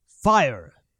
Voices / Male / Fire.wav
Fire.wav